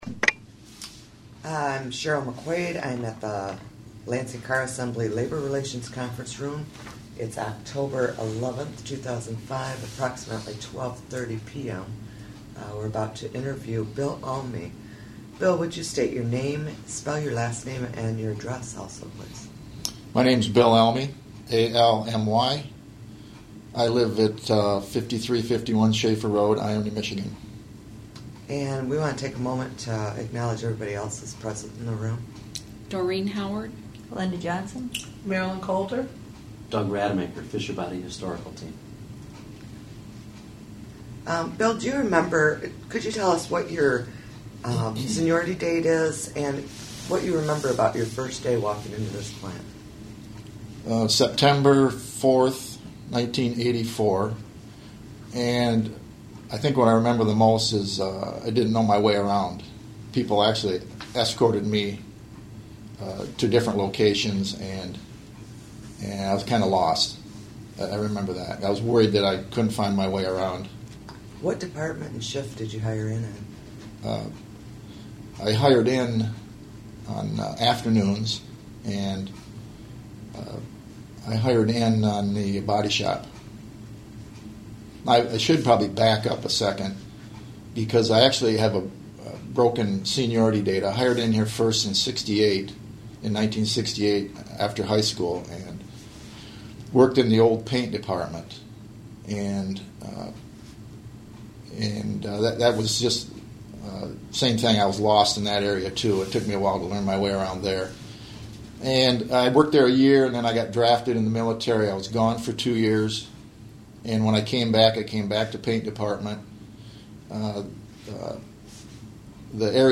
Oral History Project